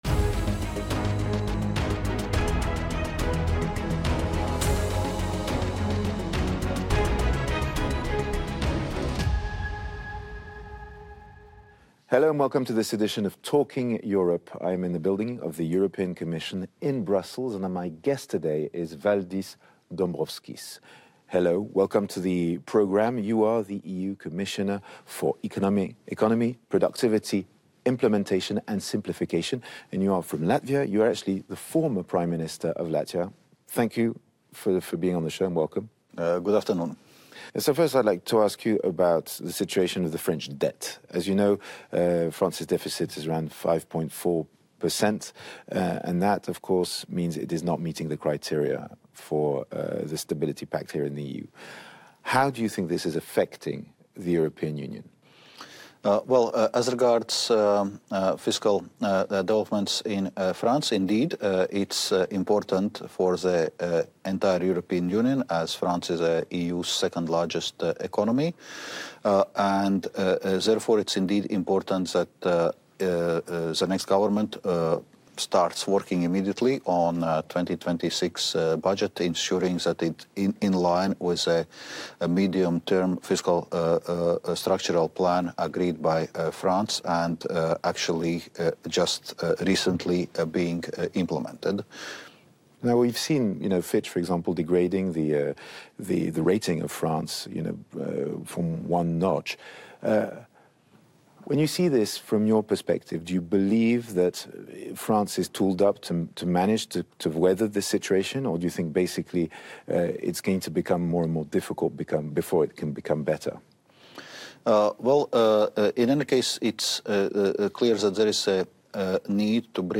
1 BONUS EP: Bobby Backstage Interview with Bailey Zimmerman & Diplo 20:12 Play Pause 15h ago 20:12 Play Pause Play later Play later Lists Like Liked 20:12 We recorded a bonus podcast backstage at our iHeartRadio Music Festival in Las Vegas inside the T-Mobile Arena. Bobby sat down with Diplo and Bailey Zimmerman to discuss how music unites us, their collaboration “Ashes,” how that all came together and more!